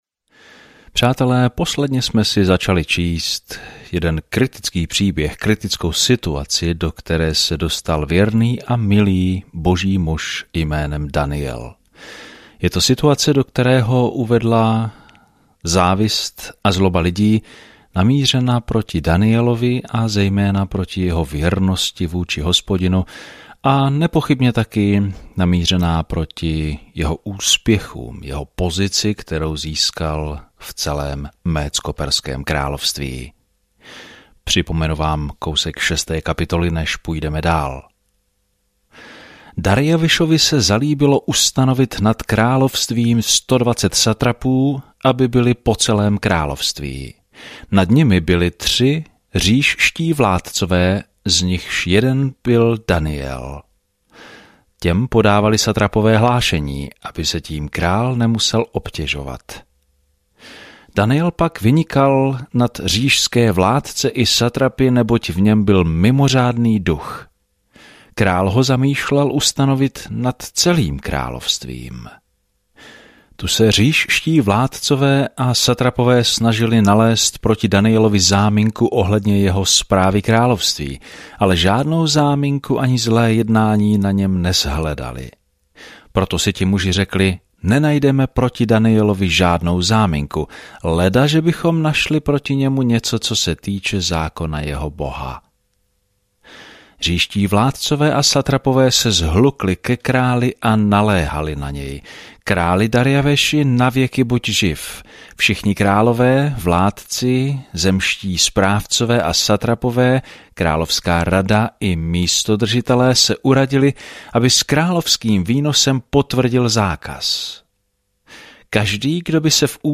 Písmo Daniel 6:10-28 Den 15 Začít tento plán Den 17 O tomto plánu Kniha Daniel je jak biografií muže, který uvěřil Bohu, tak prorockou vizí toho, kdo nakonec ovládne svět. Denně procházejte Daniela a poslouchejte audiostudii a čtěte vybrané verše z Božího slova.